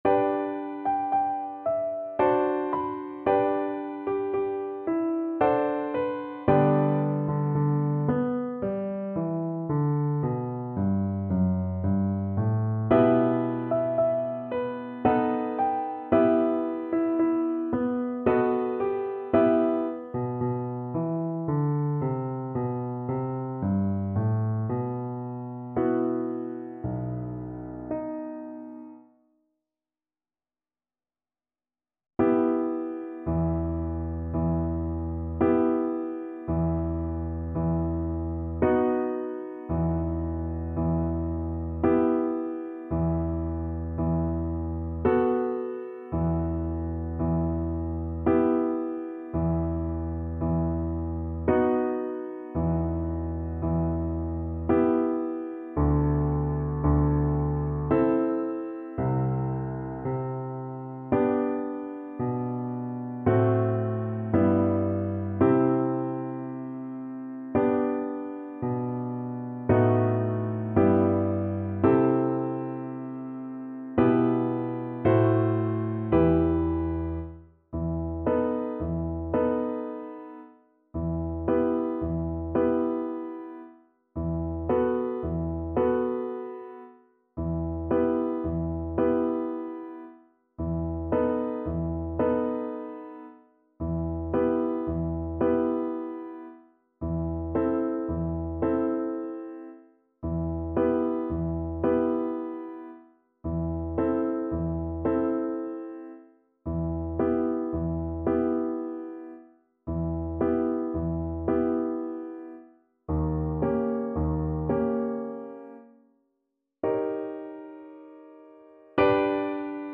~ = 56 Andante
G major (Sounding Pitch) (View more G major Music for Flute )
3/4 (View more 3/4 Music)
Classical (View more Classical Flute Music)